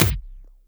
Kick OS 17.wav